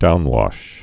(dounwŏsh, -wôsh)